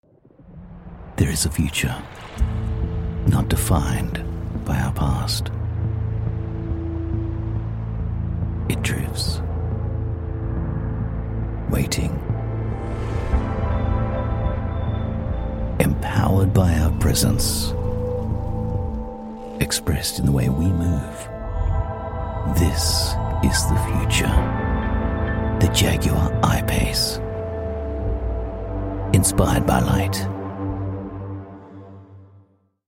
Automotive
Neumann TLM 103 mic